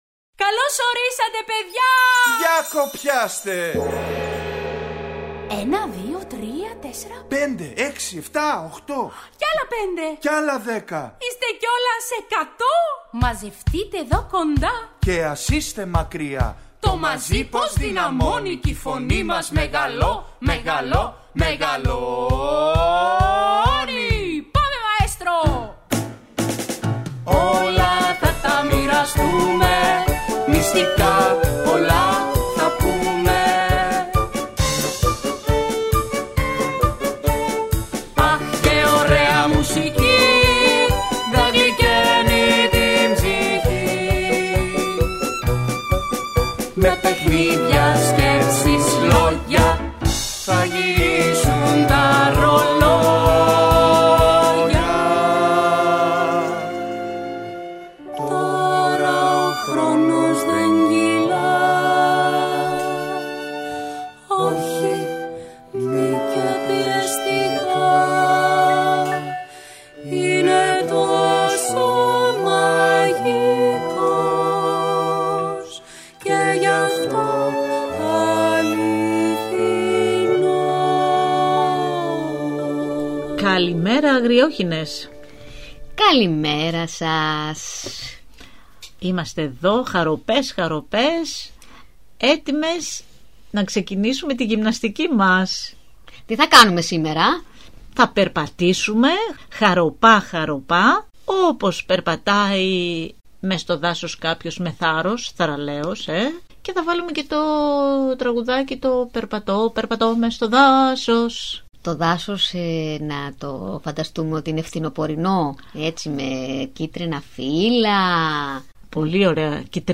Ακούστε στην παιδική εκπομπή ‘’Οι Αγριόχηνες’’ το παραμύθι “Ησυχία” των Tom Percival και Richard Jones.